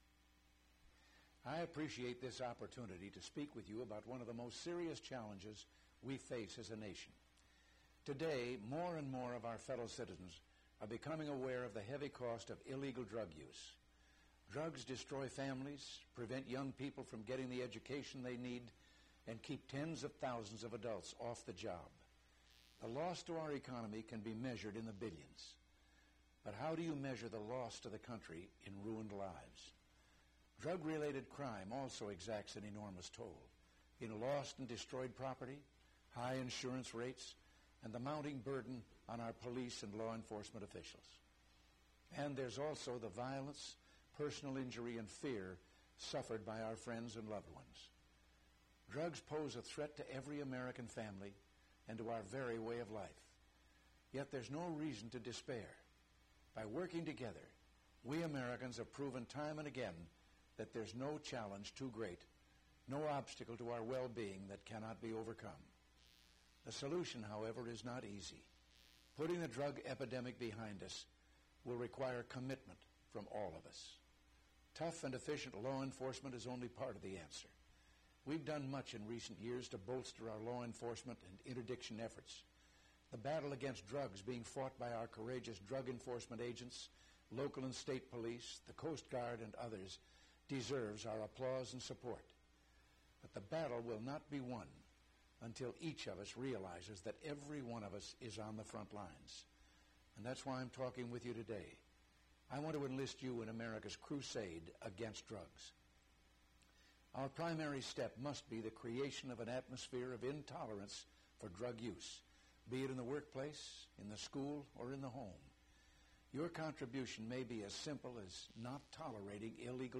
President Reagan’s Generic Drug Message, White House Library